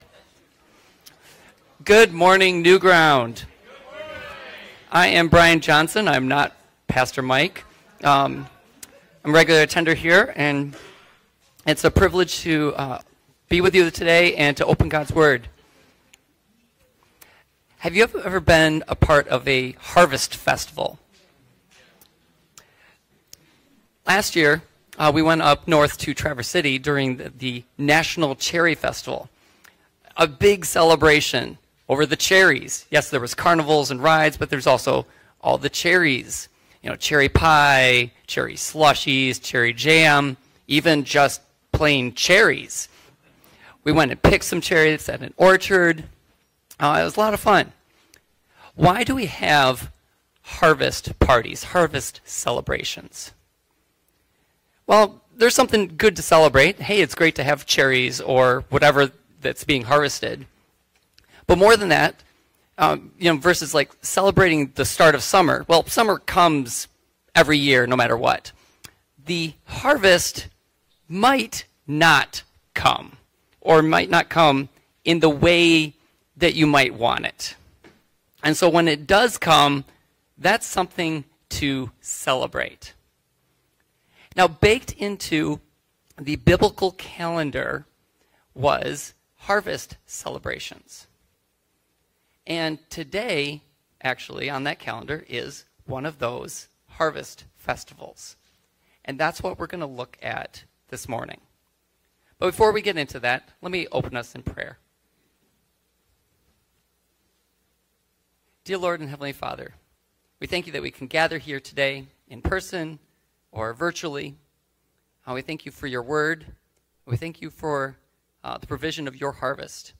Sermon: The Feast of Many Faces - Bridges to the Bible